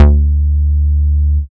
808s
Bass (4).wav